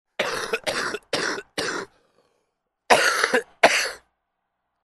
Звук кашля при COVID-19